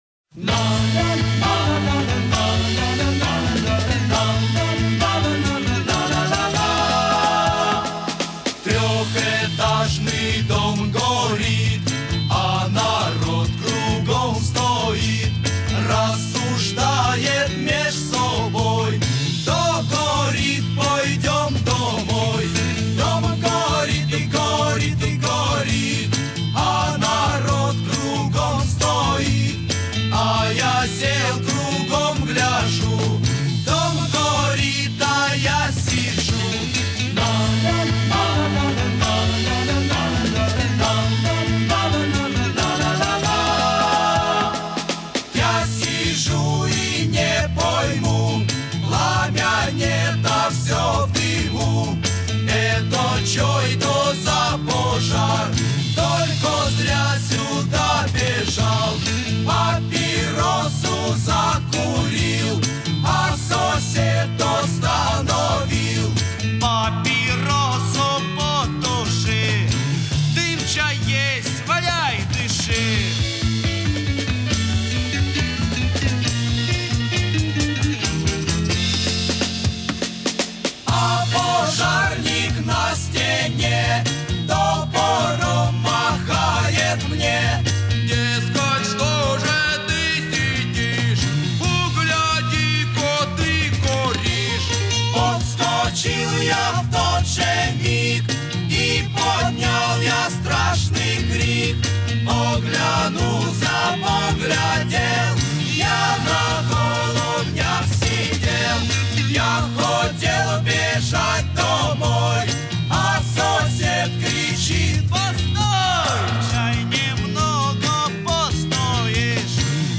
Формат:Vinyl, 7", 45 RPM, Mono
Жанр:Rock, Pop
Стиль:Pop Rock